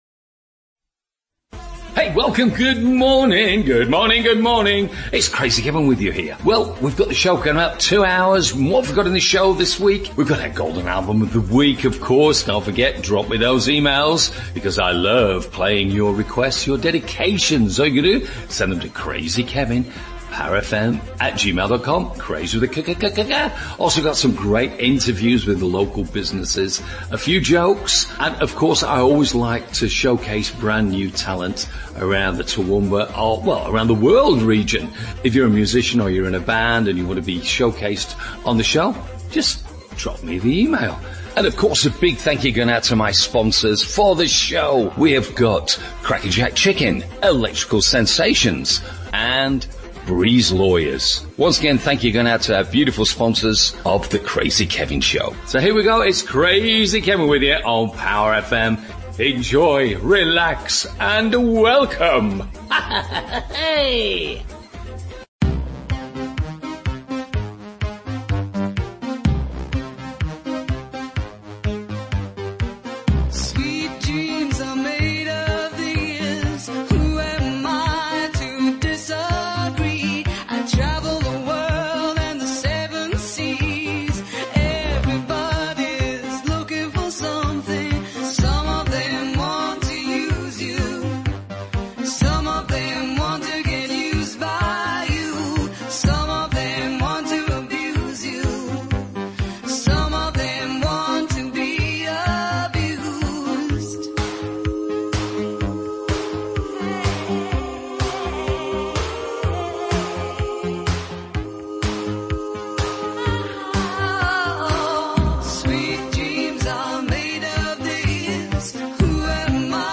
Interview with Electrical Sensations